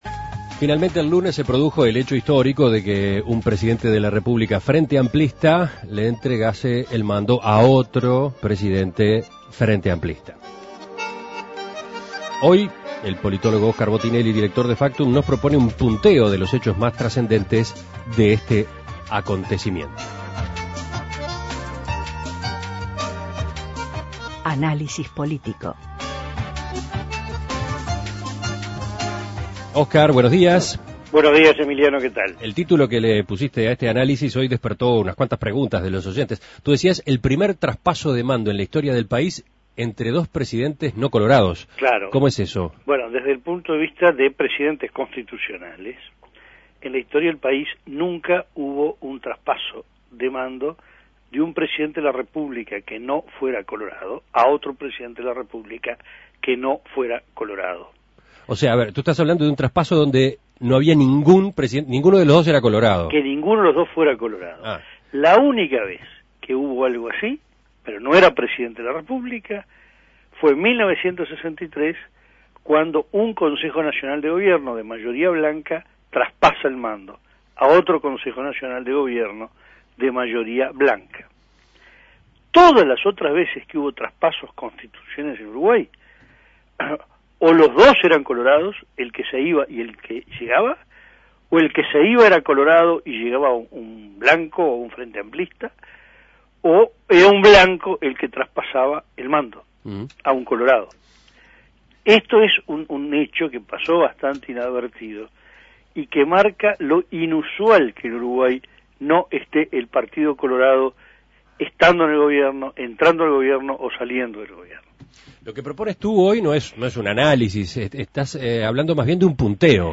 Análisis Político El primer traspaso de mando en la historia del país entre dos presidentes no colorados